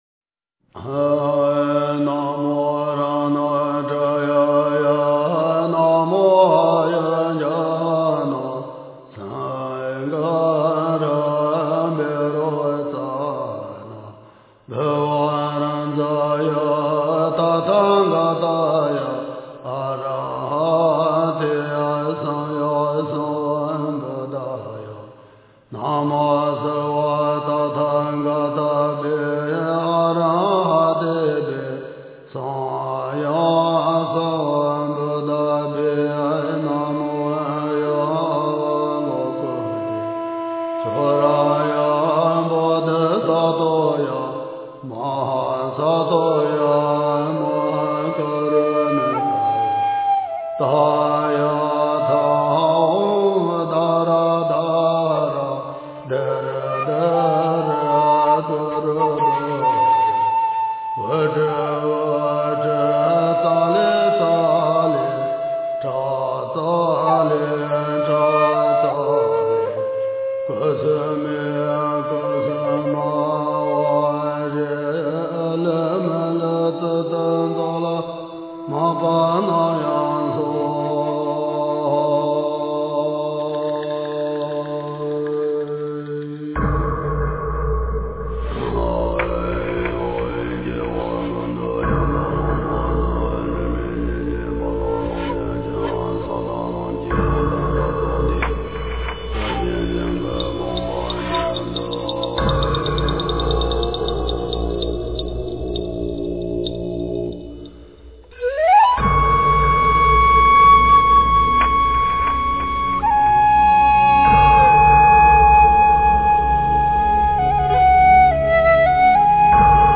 佛音 诵经 佛教音乐 返回列表 上一篇： 十一面观音根本咒 下一篇： 大悲咒 相关文章 自由自在世间行(观音灵感歌